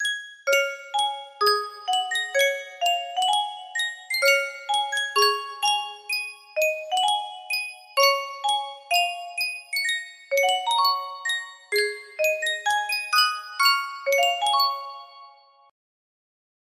Yunsheng Music Box - Umi Yukaba 6716 music box melody
Full range 60